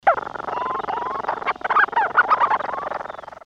głosy innych nawałników